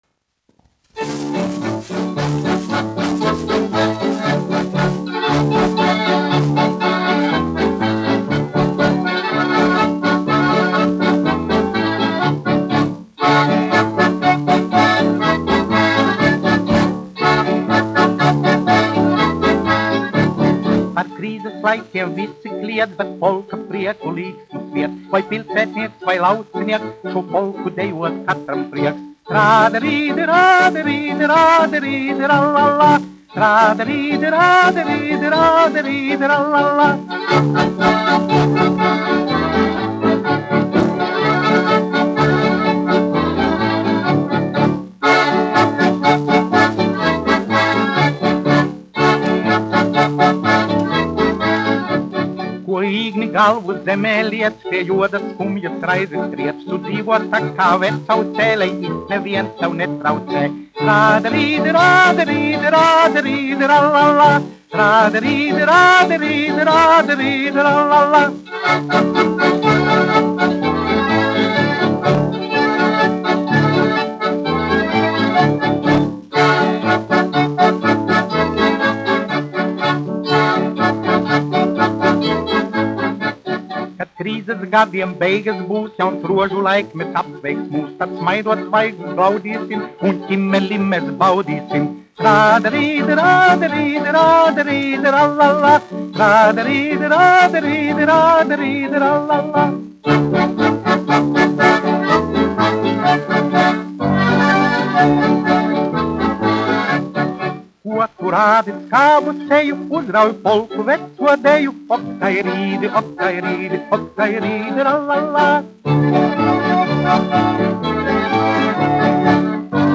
1 skpl. : analogs, 78 apgr/min, mono ; 25 cm
Polkas
Populārā mūzika
Latvijas vēsturiskie šellaka skaņuplašu ieraksti (Kolekcija)